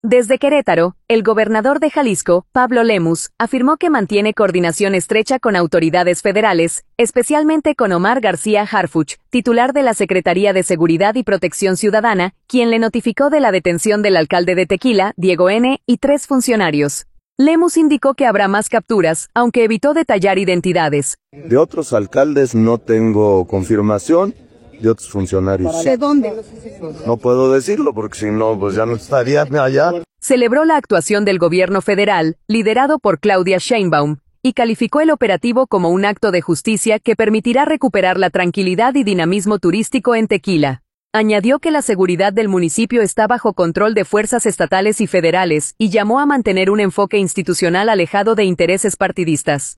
Desde Querétaro, el gobernador de Jalisco, Pablo Lemus, afirmó que mantiene coordinación estrecha con autoridades federales, especialmente con Omar García Harfuch, titular de la SSPC, quien le notificó de la detención del alcalde de Tequila, Diego “N”, y tres funcionarios. Lemus indicó que habrá más capturas, aunque evitó detallar identidades.